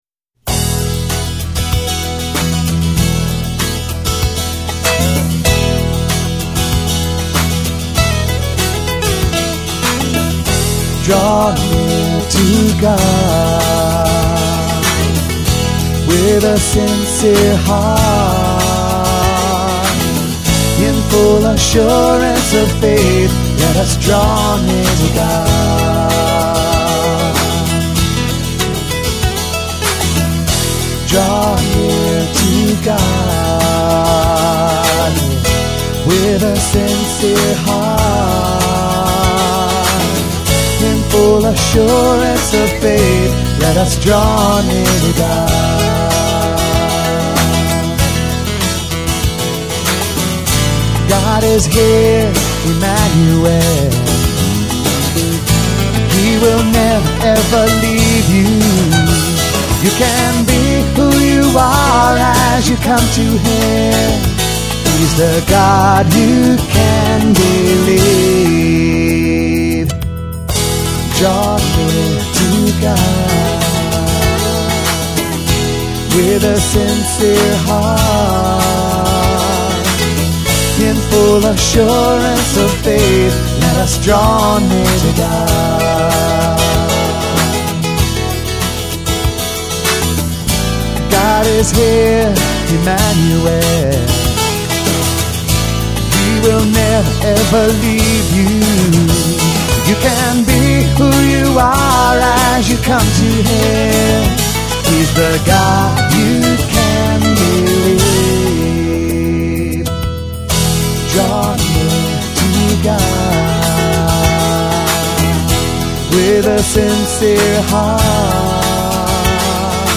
popular praise and worship songs
with a full band!